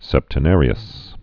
(sĕptə-nârē-əs)